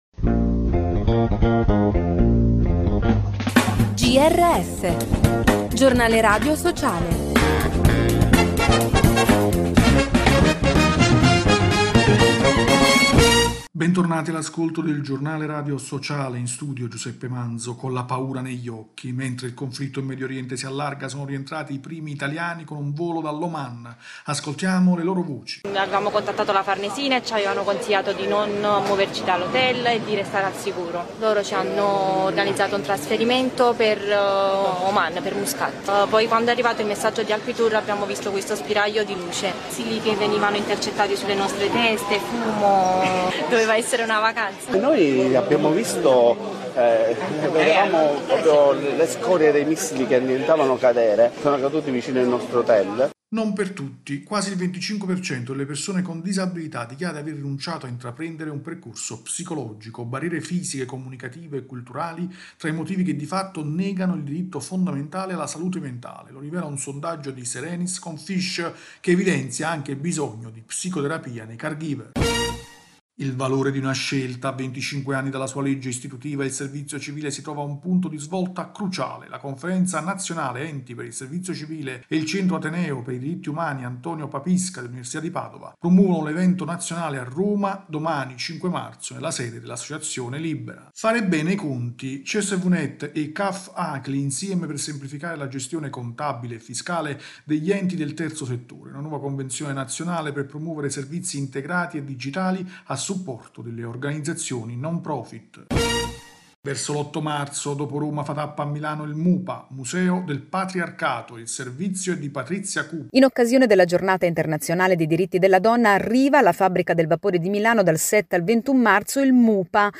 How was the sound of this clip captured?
Bentornati all’ascolto del Giornale radio sociale. In studio